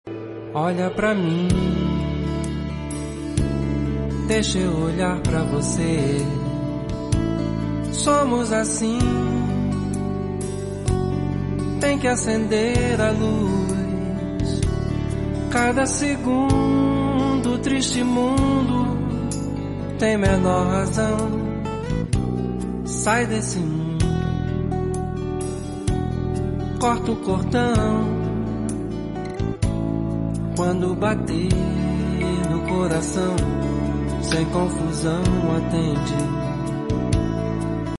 With a velvet voice gives us a romantic journey.